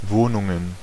Ääntäminen
Ääntäminen Tuntematon aksentti: IPA: /ˈvoːnʊŋən/ IPA: /ˈvoːnʊŋn̩/ Haettu sana löytyi näillä lähdekielillä: saksa Käännöksiä ei löytynyt valitulle kohdekielelle. Wohnungen on sanan Wohnung monikko.